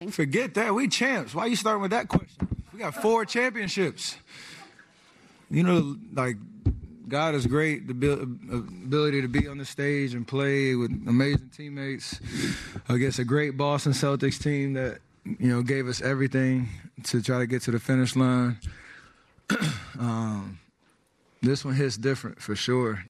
He was asked about being named the MVP.
6-17-warriors-post-being-mvp-steph-curry.mp3